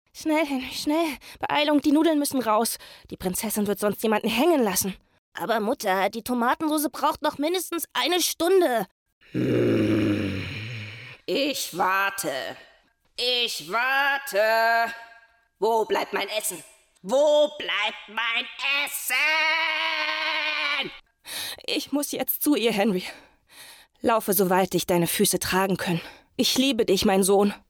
dunkel, sonor, souverän, sehr variabel, markant
Hörspiel - verschiedene Stimmen
Audio Drama (Hörspiel), Children's Voice (Kinderstimme), Trick